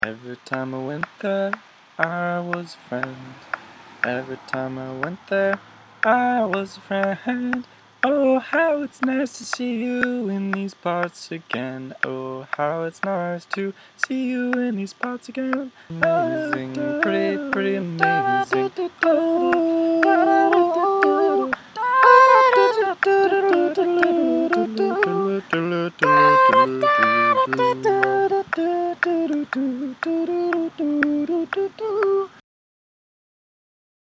You just add a vocal track, and then adjust the auto pitch on the lower left part of the screen to 100%. Here’s a ridiculous “song” I made playing around with it: Autotune Experimentation
autotune-experimentation-1.mp3